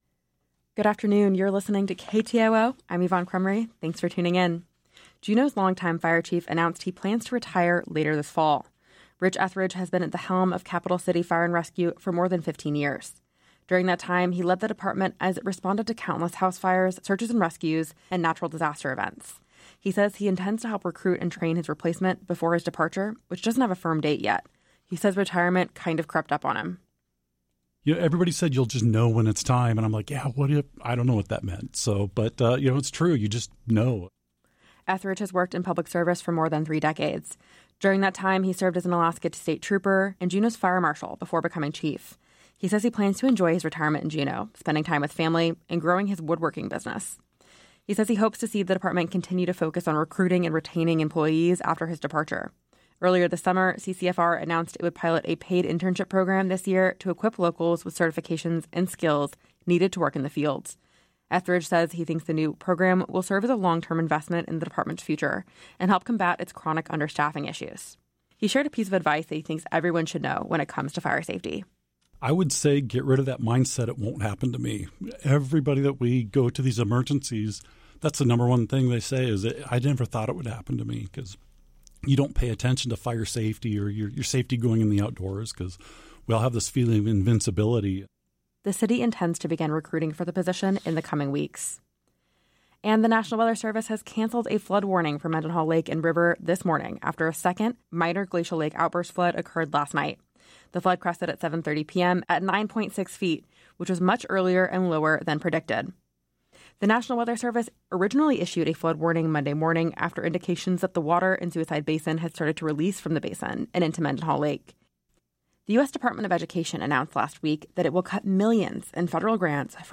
Newscast – Tuesday, Sept. 16, 2025 - Areyoupop